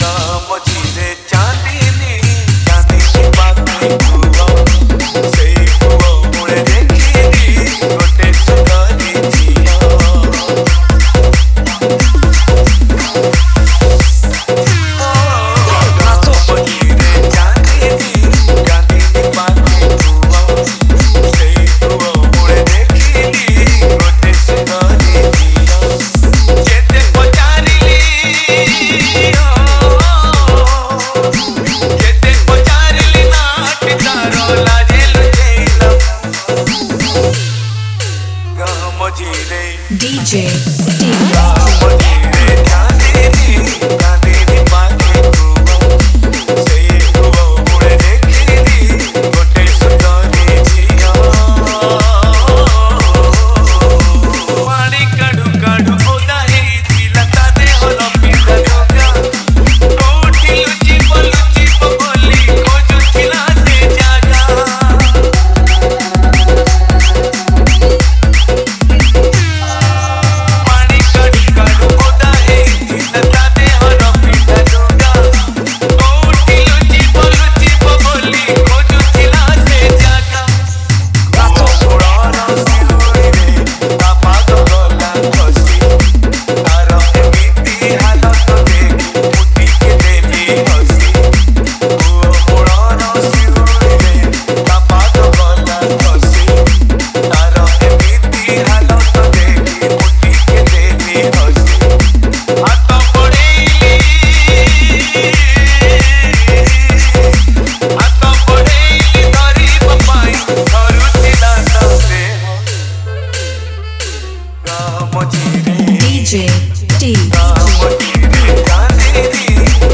Single Dj Song Collection 2022 Songs Download